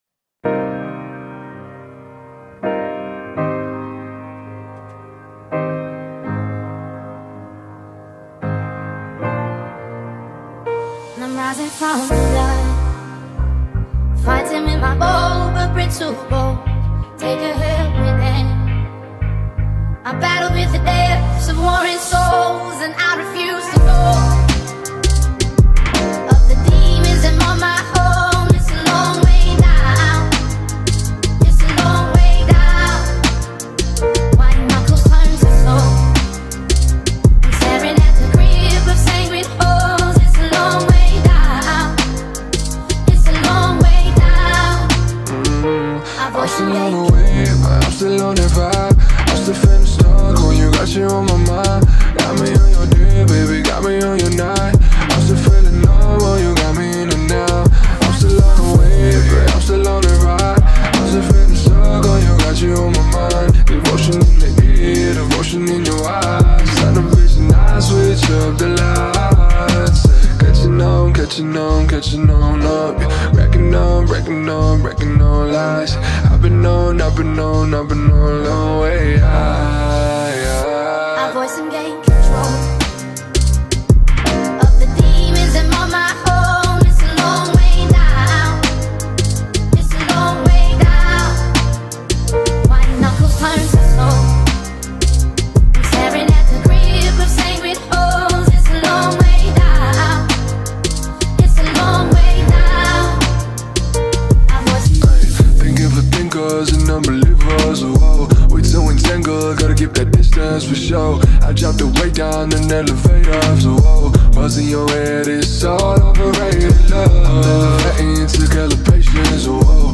это мелодичный трек в жанре поп с элементами R&B
мягкими синтезаторами и мелодичными вокалами